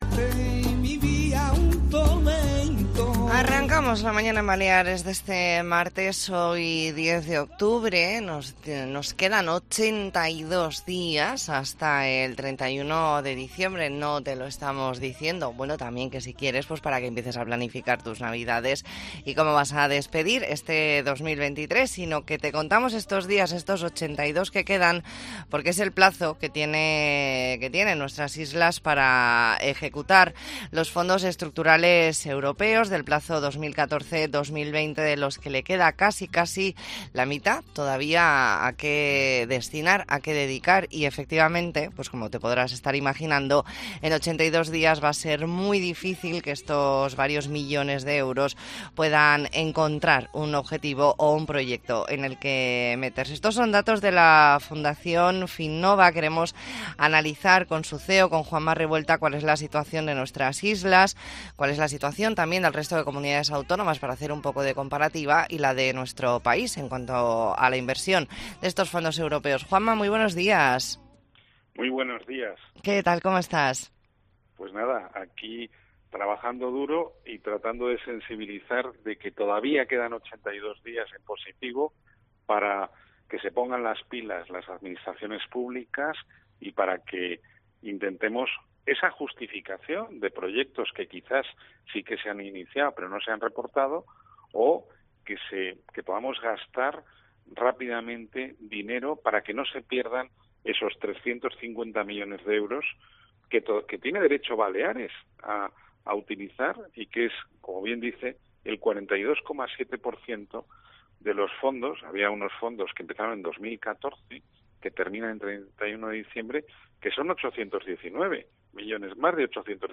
Entrevista en La Mañana en COPE Más Mallorca, martes 10 de octubre de 2023.